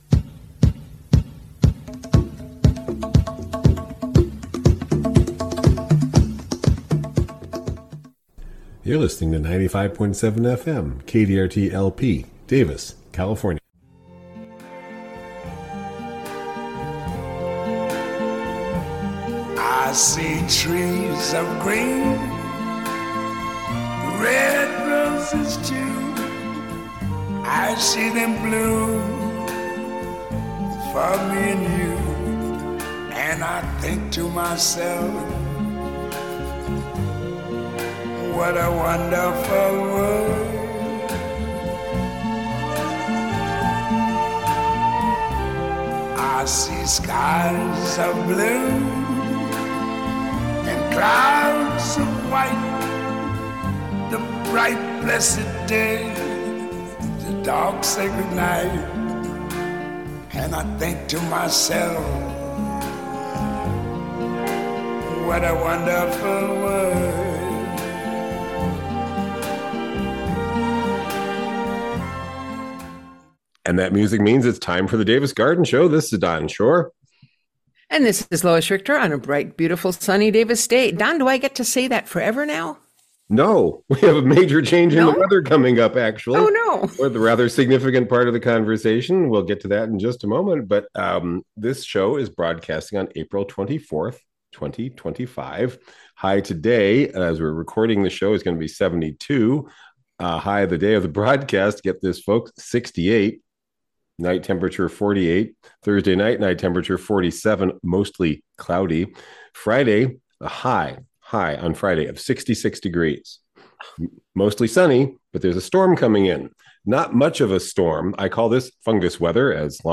Spring gardening conversations